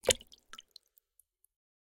7f0caa168b Divergent / mods / Bullet Shell Sounds / gamedata / sounds / bullet_shells / generic_water_3.ogg 34 KiB (Stored with Git LFS) Raw History
generic_water_3.ogg